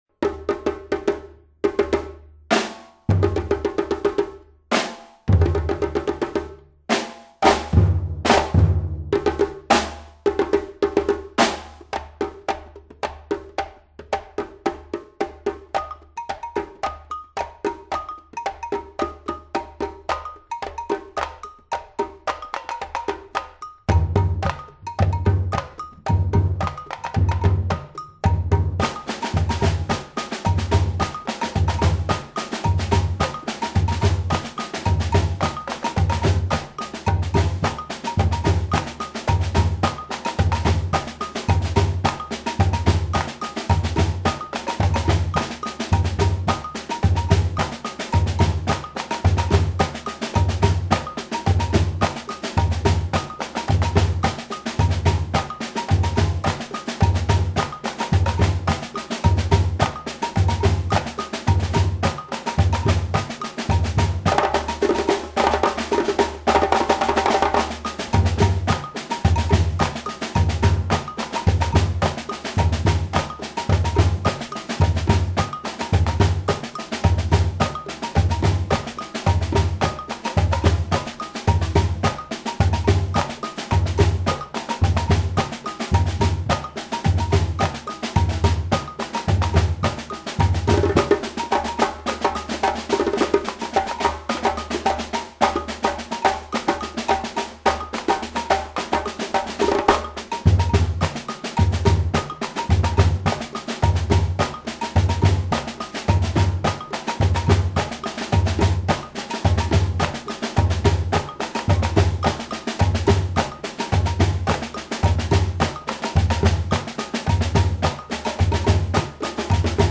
BRAZILIAN PERCUSSION_